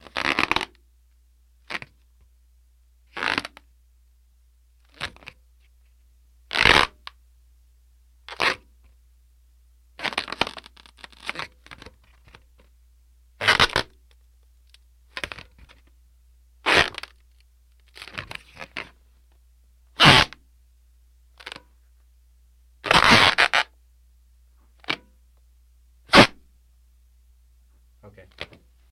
Leather Egg Squeaks